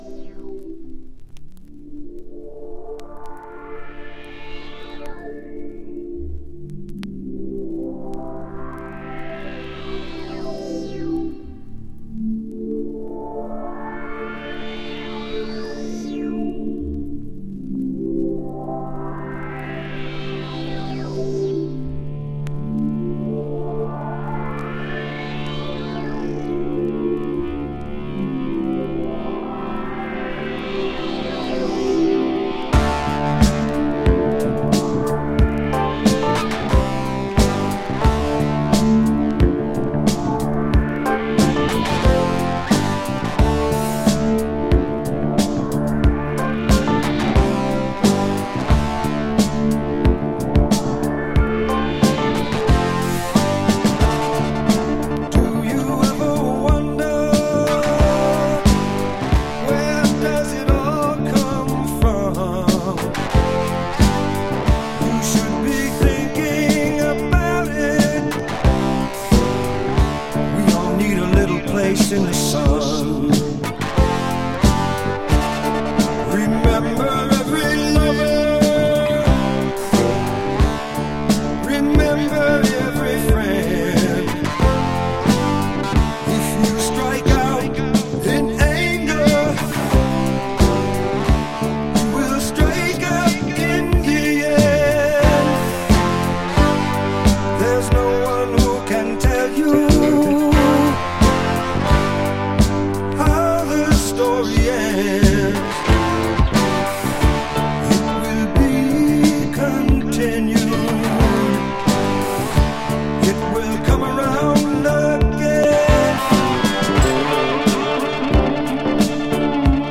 シカゴ産オブスキュア・スペースロック！
シカゴを拠点に活動したギタリスト。
【COSMIC】【BLUES ROCK】